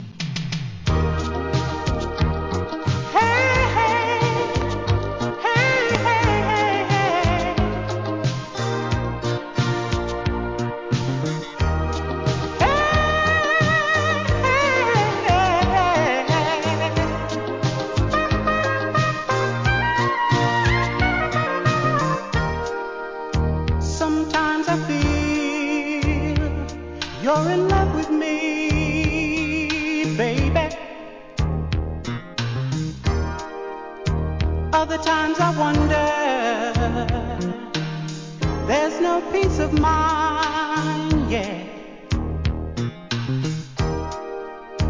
1. SOUL/FUNK/etc...
1987年、美メロSOUL!!